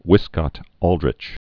(wĭskŏt-ôldrĭch, -ŏl-, vĭs-)